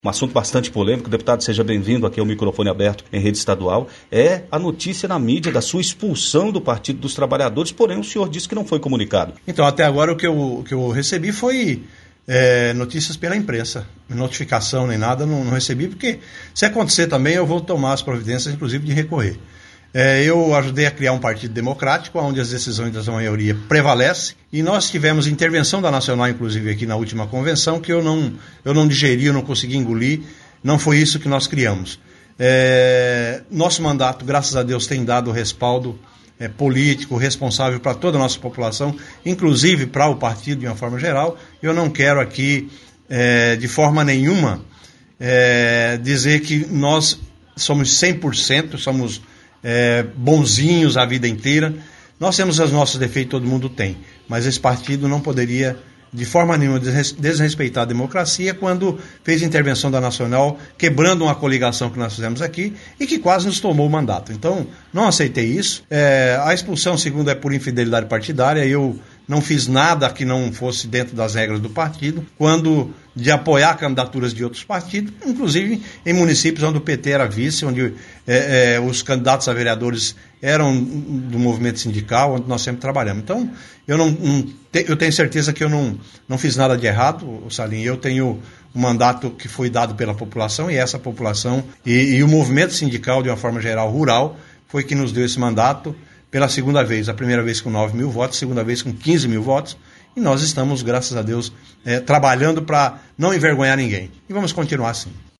Lazinho da Fetagro durante entrevista, na Massa FM Jaru
Entrevista